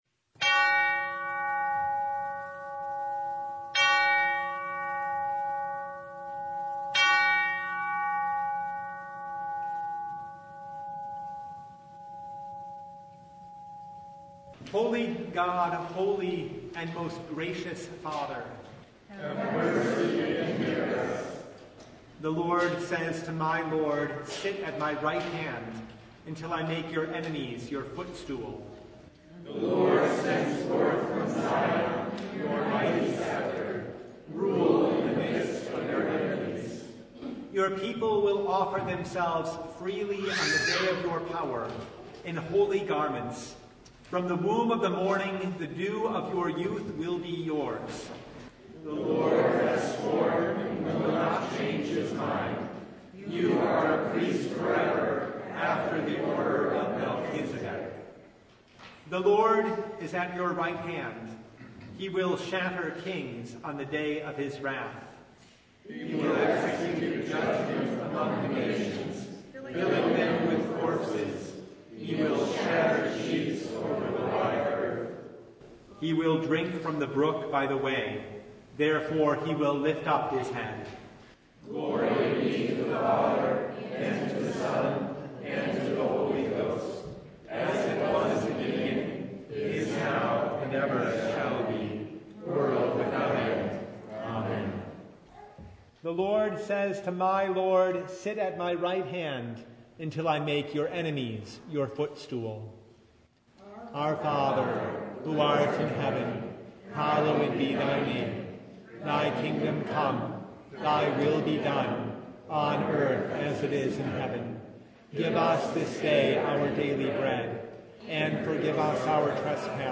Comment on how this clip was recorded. Service Type: Advent Noon